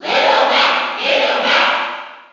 File:Little Mac Cheer NTSC SSB4.ogg
Little_Mac_Cheer_NTSC_SSB4.ogg.mp3